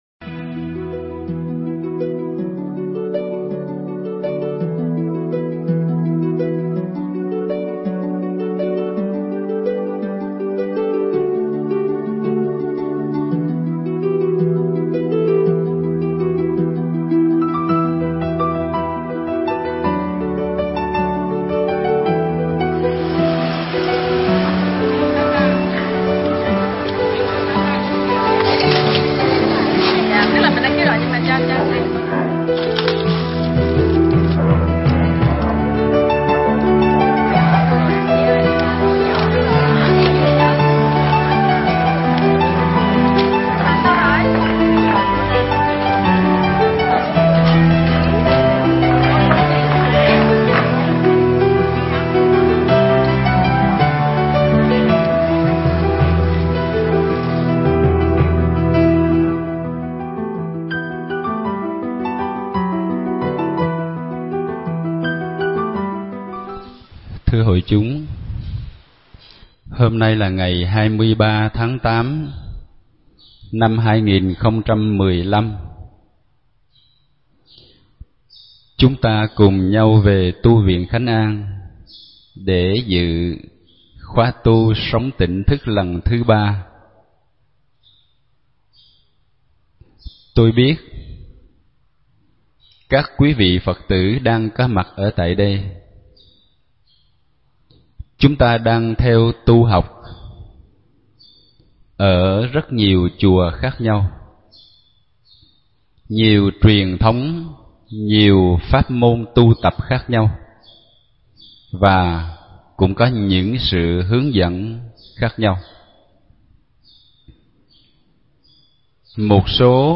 Mp3 thuyết pháp Chánh Niệm Trong Các Pháp Môn Tu Tập